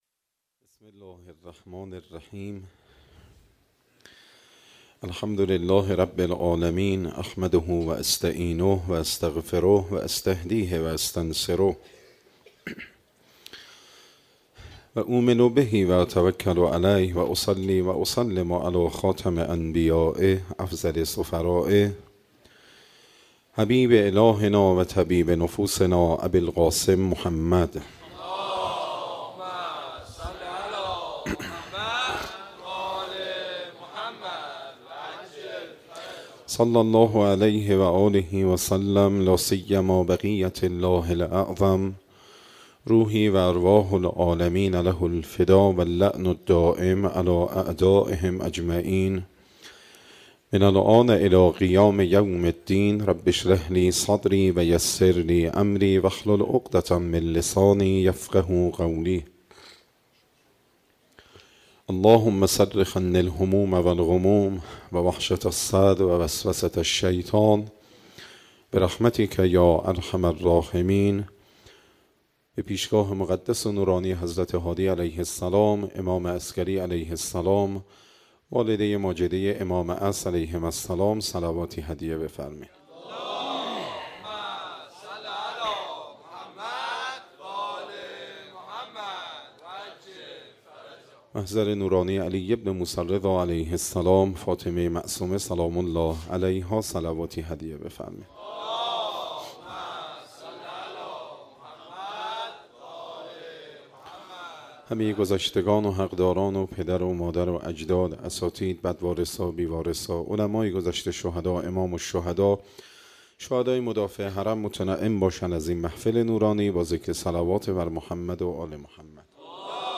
شب 7 ماه رمضان - هیئت مکتب الزهرا سلام الله علیها